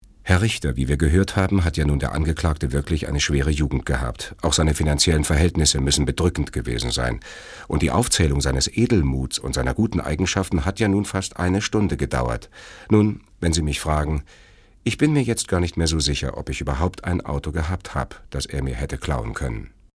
Lip-Sync (Synchron)